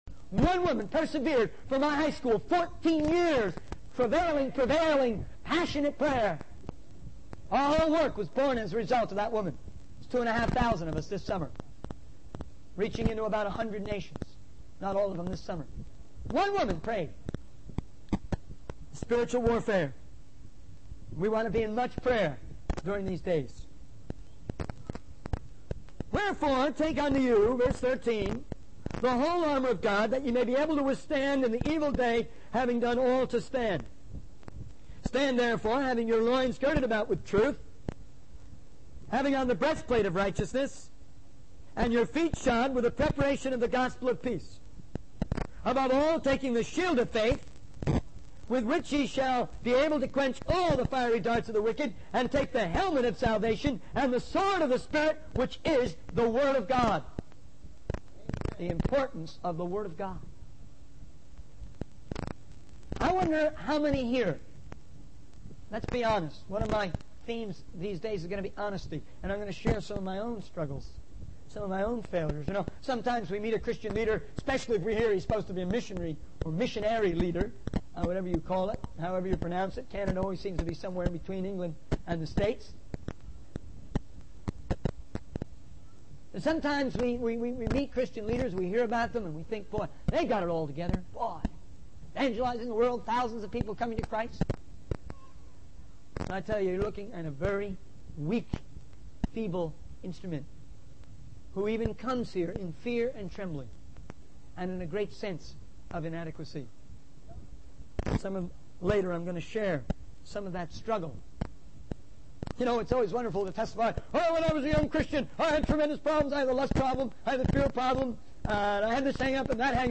In this sermon, the speaker emphasizes the importance of building a strong foundation in the faith. He dismisses the idea that faith is merely a religious system or an emotional drive, but rather a conscious decision to follow Jesus.